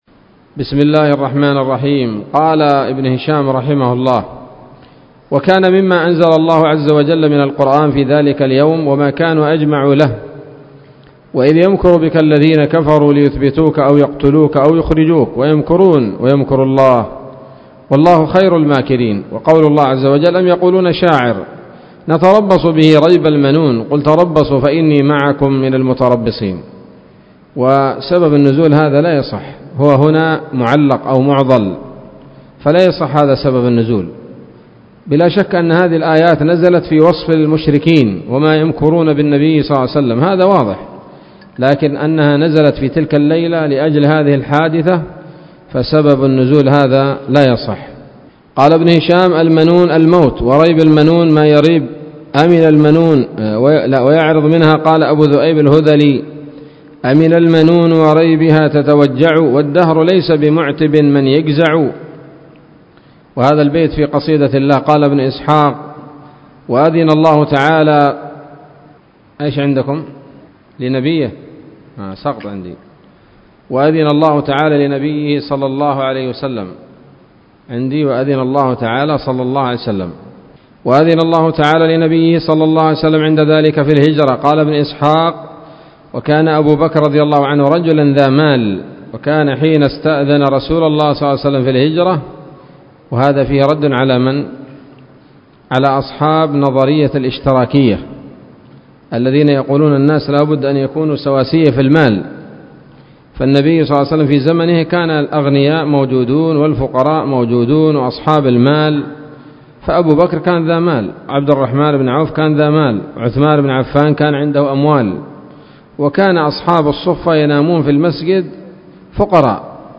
الدرس الثالث والسبعون من التعليق على كتاب السيرة النبوية لابن هشام